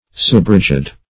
Subrigid \Sub*rig"id\, a. Somewhat rigid or stiff.